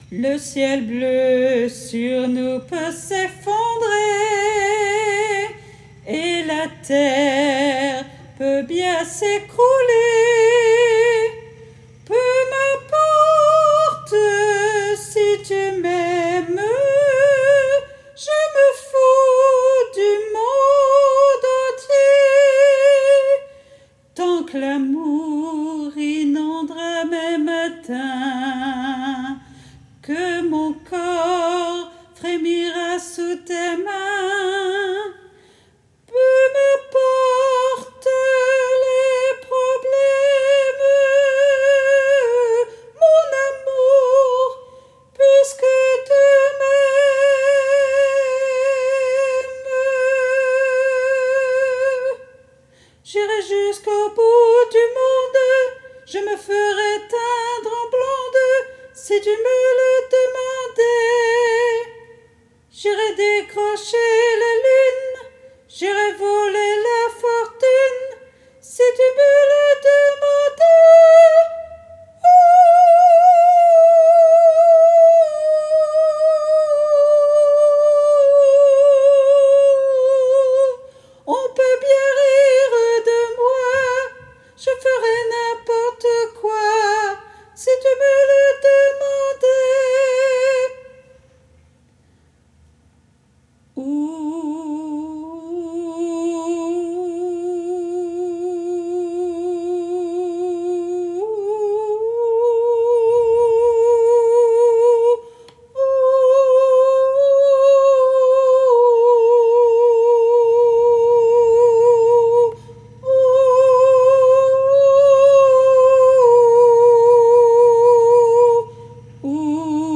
MP3 versions chantées
Tenor